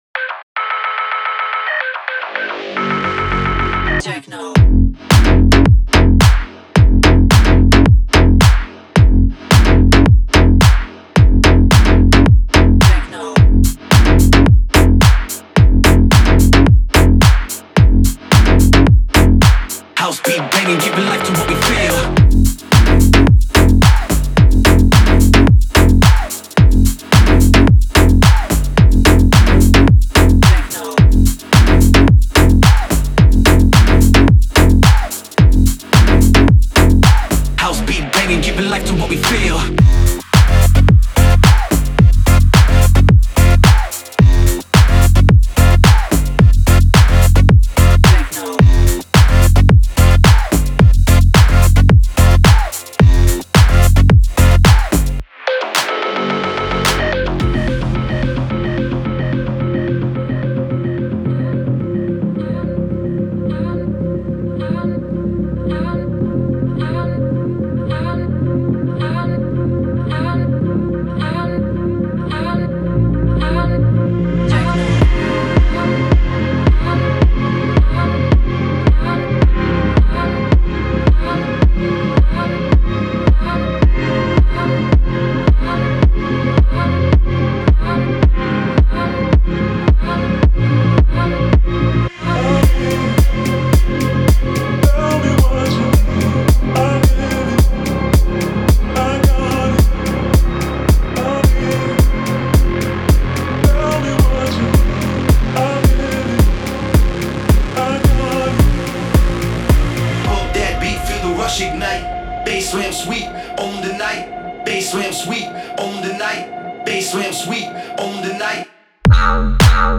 Текст песни ДИНАМИЧНАЯ МУЗЫКА Музыка